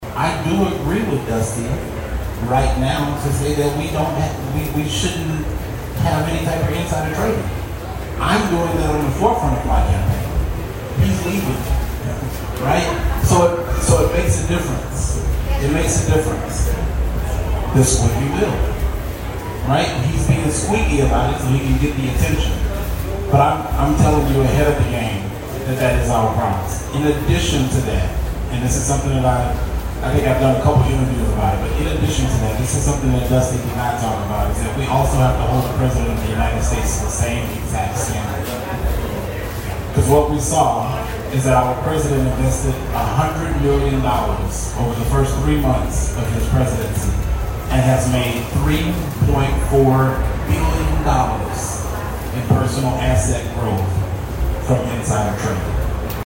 in the town hall the Democrat Party hosted on Saturday